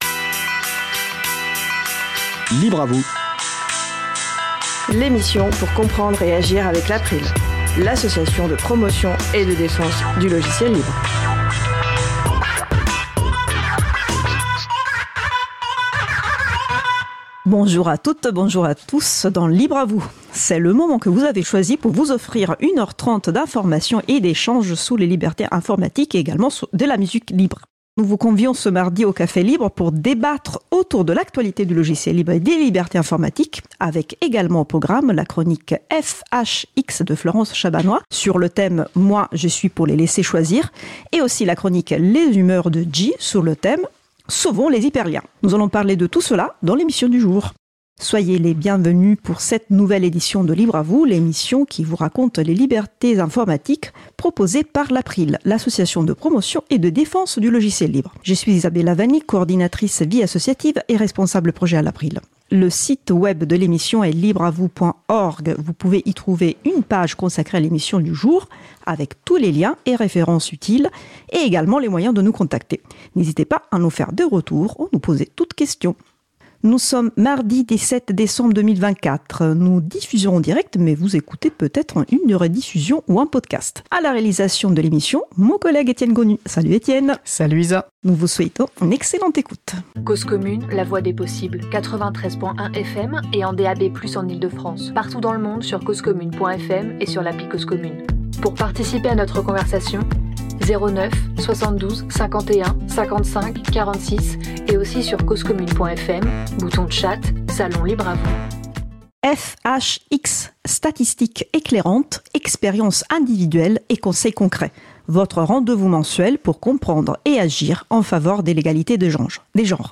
Émission Libre à vous ! diffusée mardi 17 décembre 2024 sur radio Cause Commune